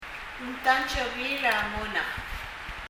発音
ここでは、文頭のNgが明瞭に聴こえます。